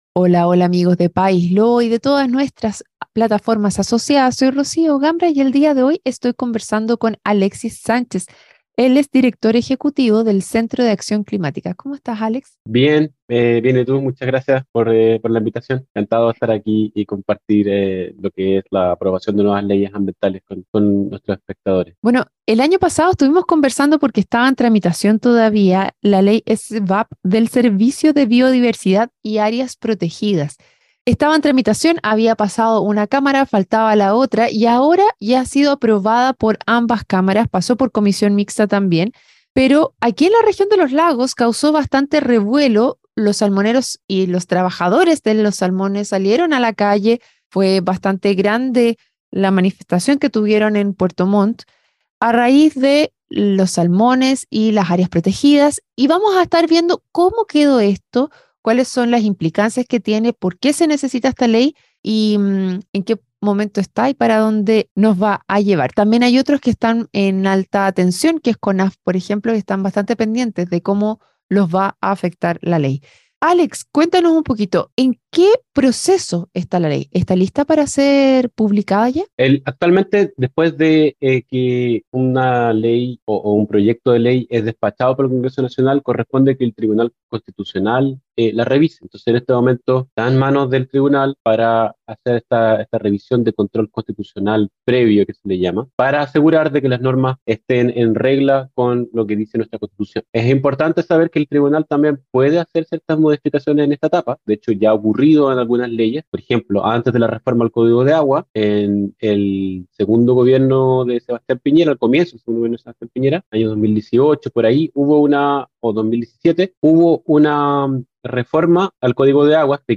programa de entrevistas